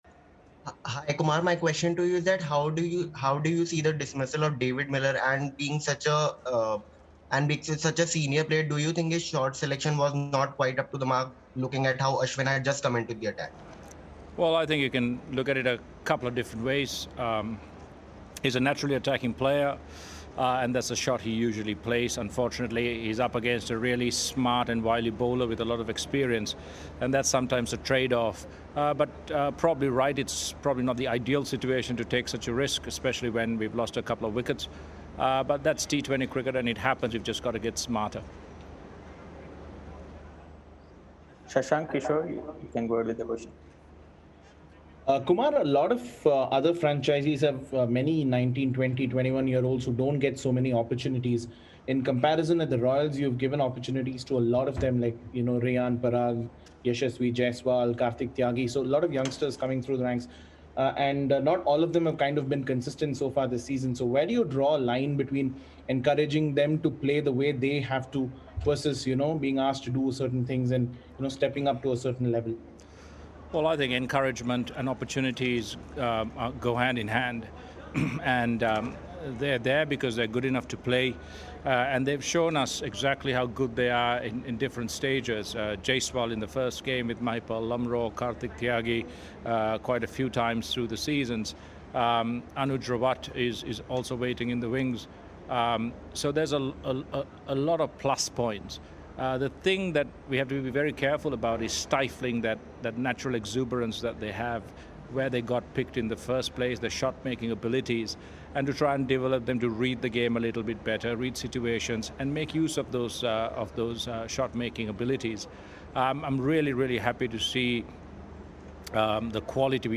Kumar Sangakkara of Rajasthan Royals and Anrich Nortje of Delhi Capitals addressed the media after the game.